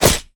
damage_wolf1.ogg